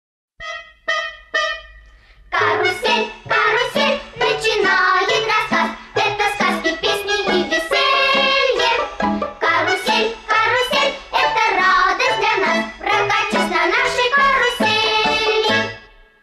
Детская хоровая композиция про карусель из мультфильма